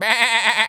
Animal_Impersonations
sheep_baa_bleat_high_01.wav